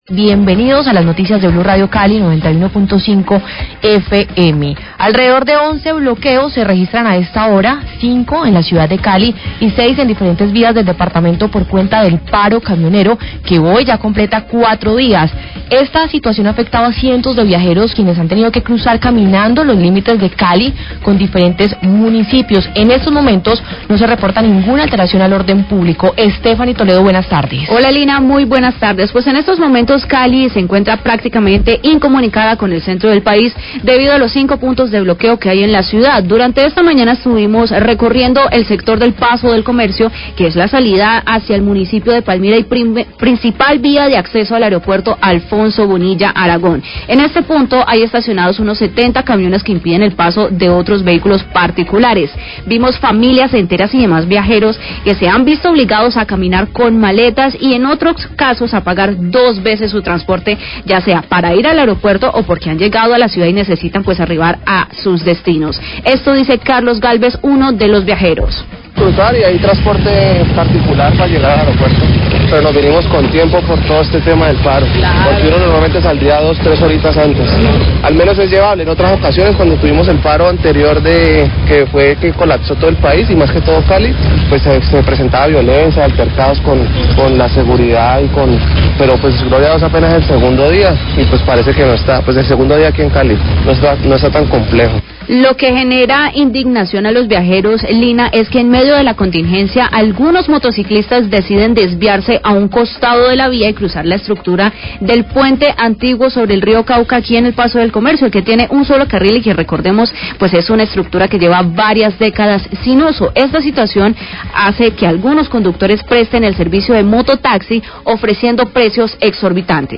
Alcalde Eder habla de los puntos de bloqueo en Cali y corredores humanitarios
Radio
Los bloqueos de vías por el paro de camioneros viene afectando a viajeros y pacientes, los cuales no pueden acceder a medicamentos ni cumplir con sus citas médicas. El Alcalde de Cali, Alejandro Eder, dice que se están en negociaciones con quienes están en los bloqueos pero aclara que las negociaciones sobre el precio del ACPM, le corresponde al gobierno nacional. Agrega que el diálogo ha permitido mantener corredores humanitarios.